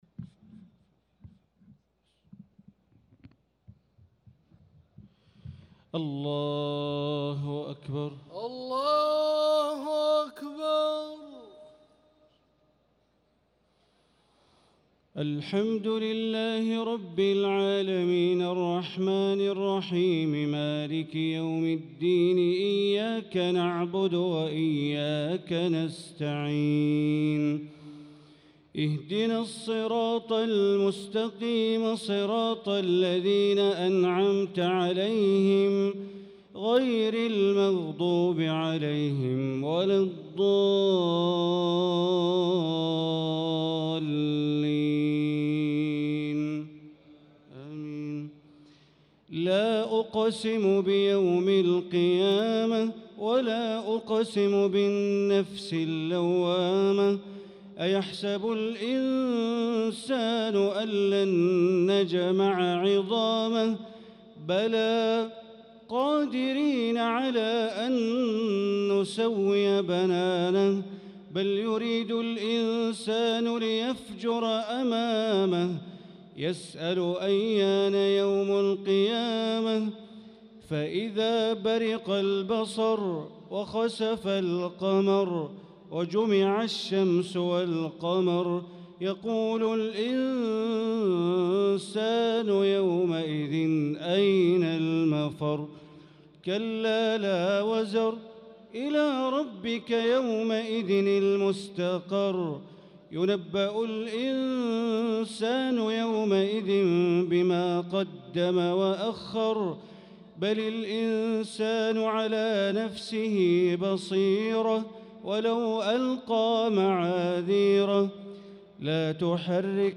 صلاة التراويح ليلة 28 رمضان 1445 للقارئ ماهر المعيقلي - الثلاث التسليمات الأولى صلاة التراويح